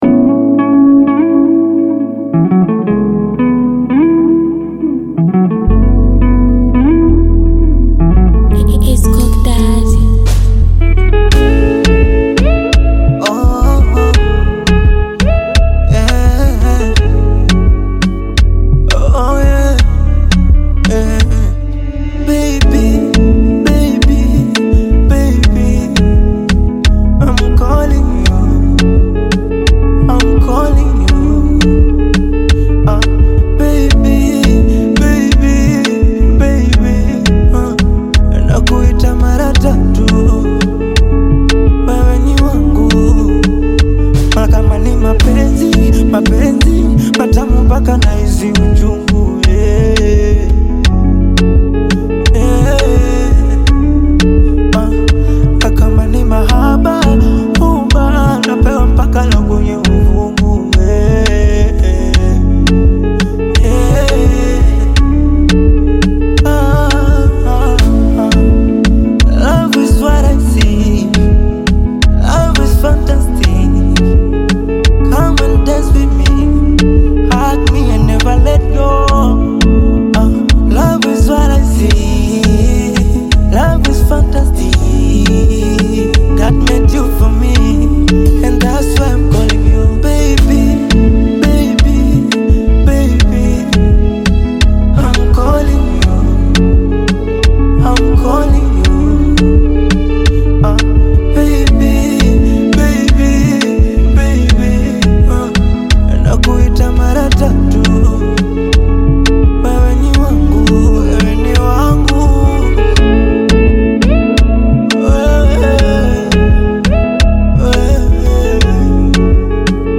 Bongo Flava music track
Bongo Flava song
This catchy new song